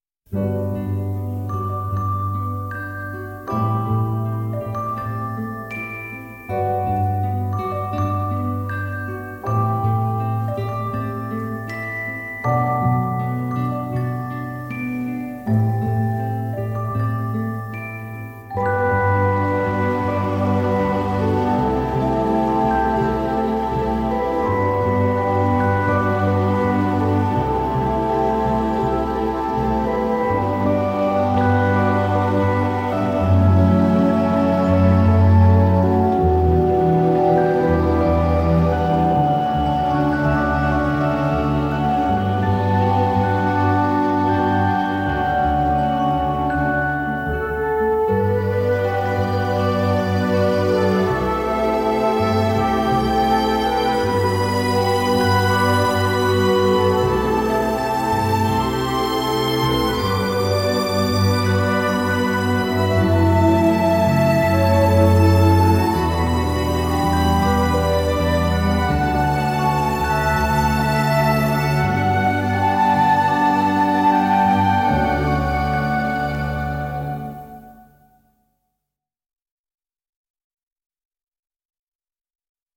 radiomarelamaddalena / STRUMENTALE / ORCHESTRE /
Original Motion Picture Soundtrack